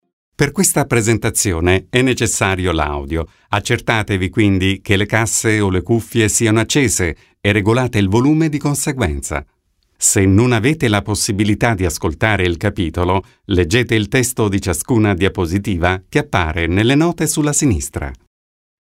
Sprecher italienisch.
Sprechprobe: eLearning (Muttersprache):
Corporate 2 E-Learning.mp3